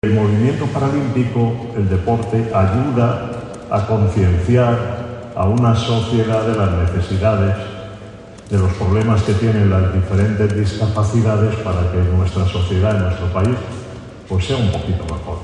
Foto de familia del acto de acogida en Madrid del equipo paralímpicoLa ministra de Educación, Formación Profesional y Deportes, Pilar Alegría, junto al ministro de Derechos Sociales, Consumo y Agenda 2030, Pablo Bustinduy, homenajearon el 10 de seprtiembre en Madrid al Equipo Paralímpico Español, tras el éxito logrado en los Juegos de París 2024 en los que ha logrado un total de 40 medallas.